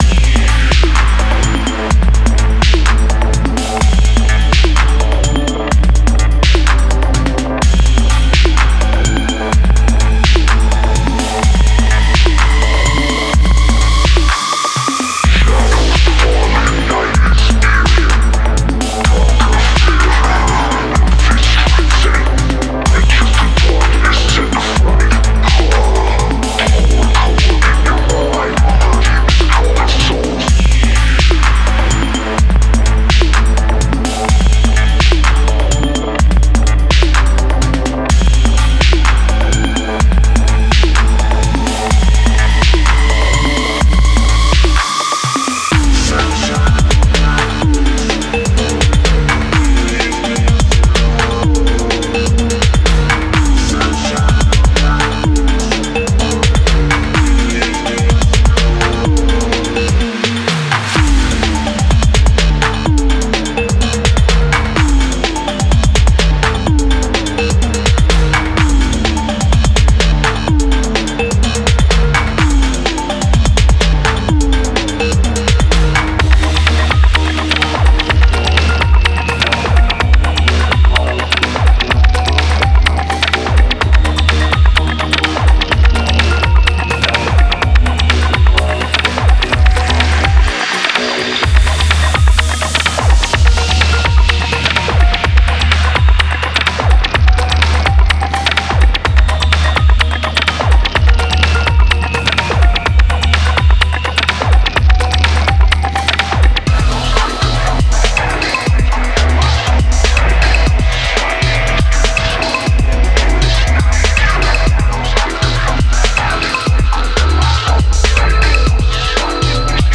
Genre:Electro
グルーヴ、テクスチャ、メロディ要素を収録し、ドライビングで催眠的なリズムを作り出します。
グルーヴと反復を重視し、微細な変化や進化するシーケンス、最小限のメロディフレーズで徐々に緊張感と動きを生み出します。
デモサウンドはコチラ↓
9 Vocoder Vocals